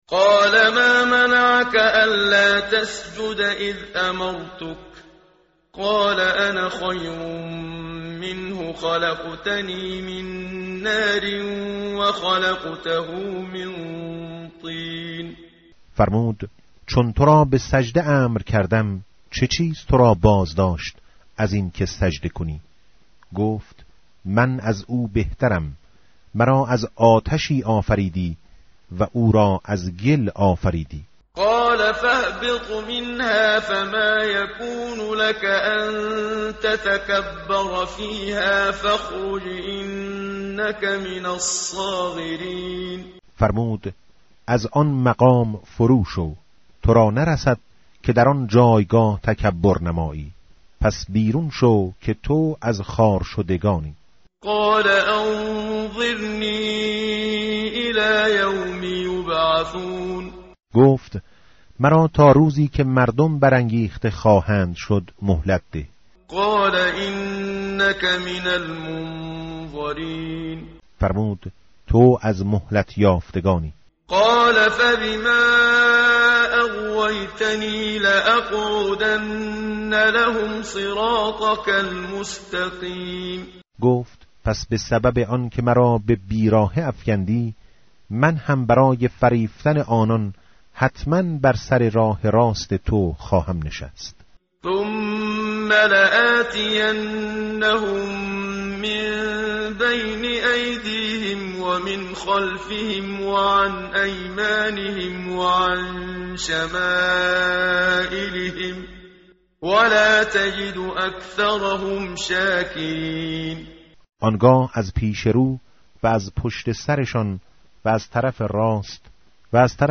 متن قرآن همراه باتلاوت قرآن و ترجمه
tartil_menshavi va tarjome_Page_152.mp3